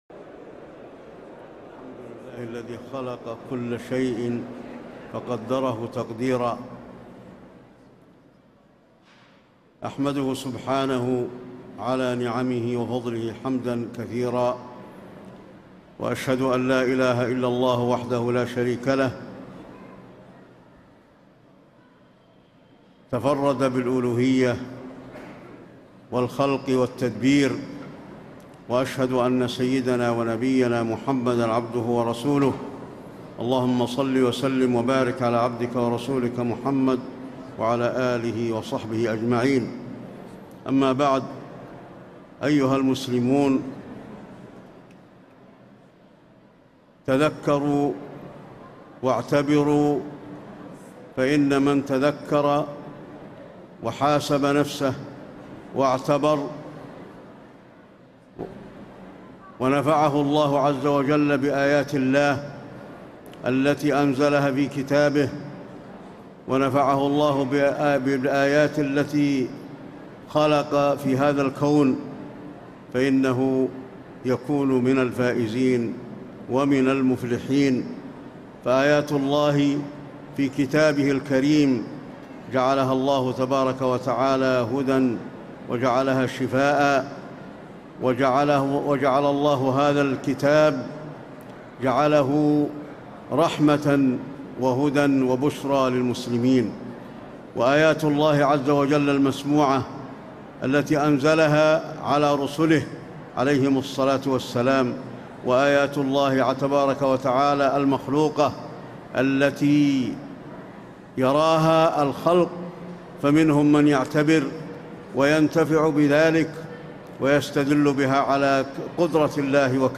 خطبة الخسوف المدينة- الشيخ علي الحذيفي
تاريخ النشر ١٥ جمادى الآخرة ١٤٣٤ هـ المكان: المسجد النبوي الشيخ: فضيلة الشيخ د. علي بن عبدالرحمن الحذيفي فضيلة الشيخ د. علي بن عبدالرحمن الحذيفي خطبة الخسوف المدينة- الشيخ علي الحذيفي